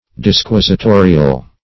Disquisitorial \Dis*quis`i*to"ri*al\